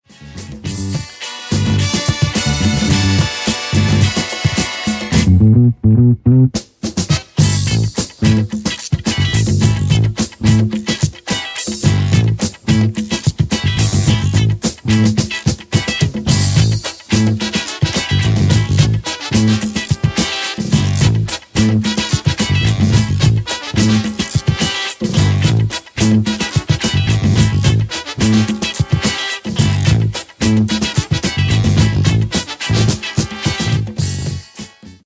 Genere: Funk | Soul